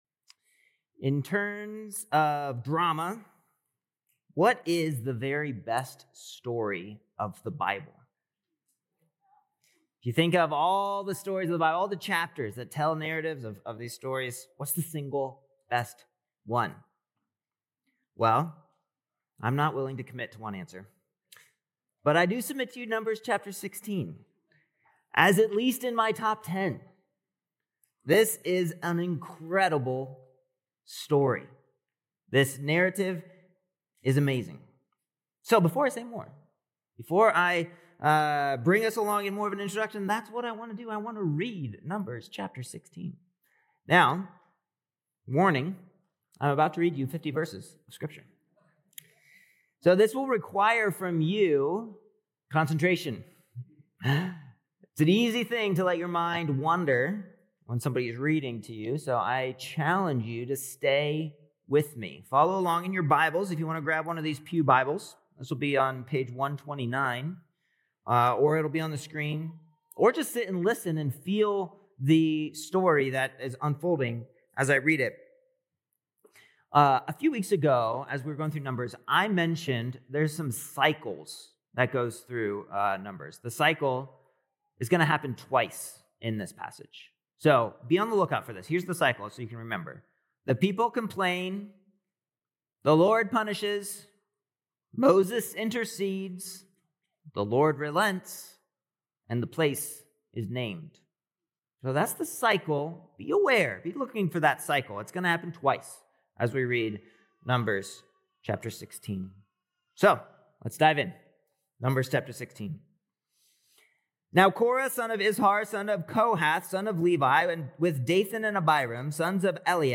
August 31st Sermon